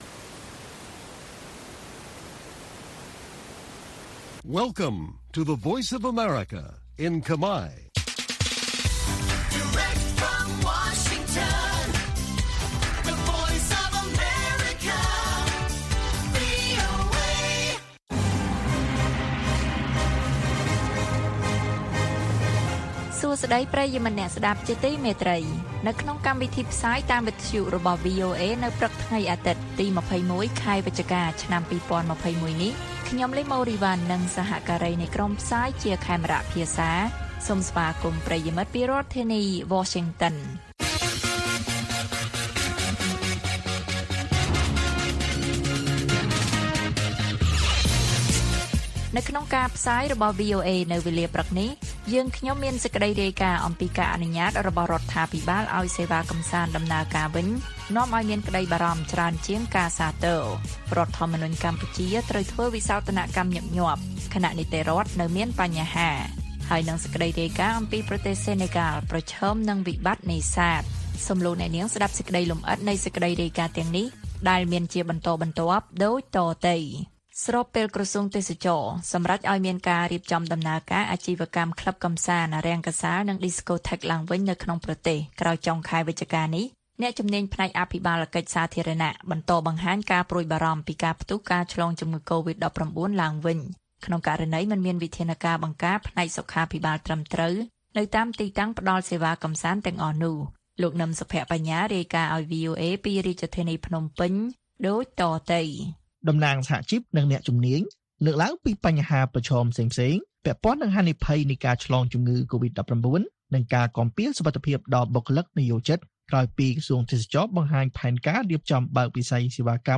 ព័ត៌មានពេលព្រឹក៖ ២១ វិច្ឆិកា ២០២១